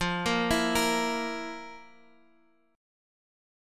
FM7sus4 Chord